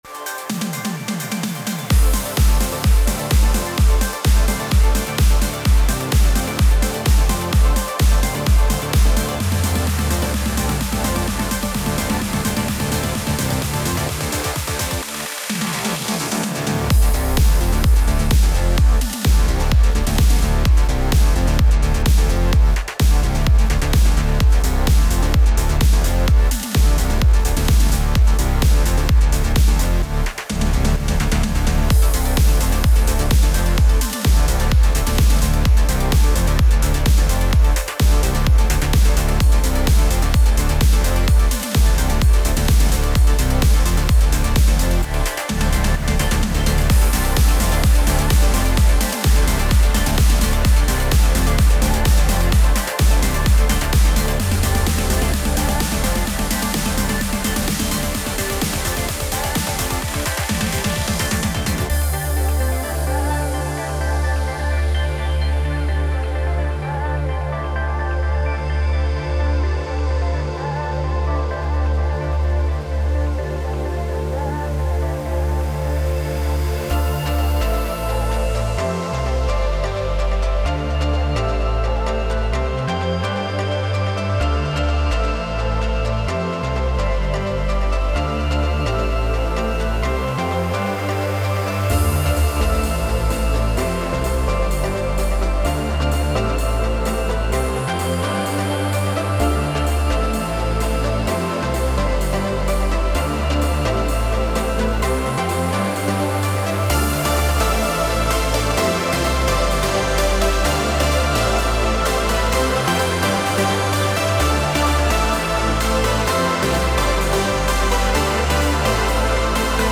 موسیقی بی کلام پر‌انرژی ترنس ورزشی